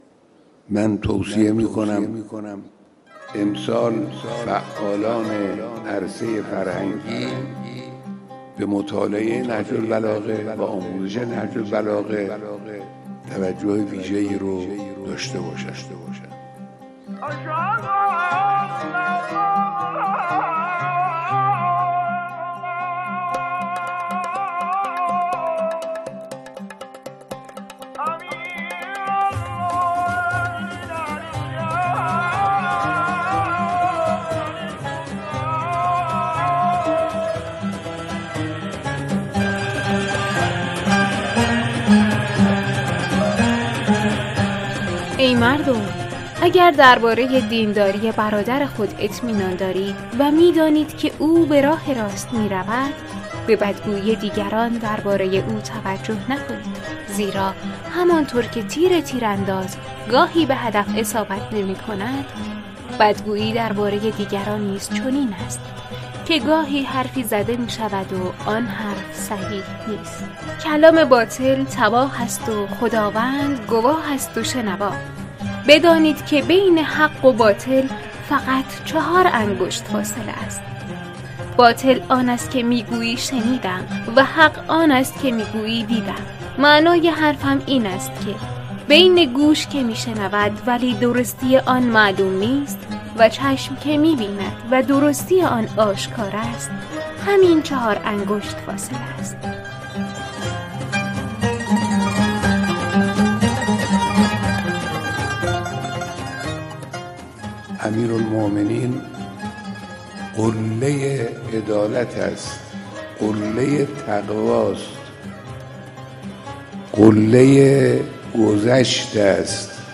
در جریان این پویش از دانشجویان، استادان و کارکنان خواسته شد که از هر نامه، کلام و خطبه‌های نهج‌البلاغه را که دوست دارند با صدا خود خوانده و ارسال کنند.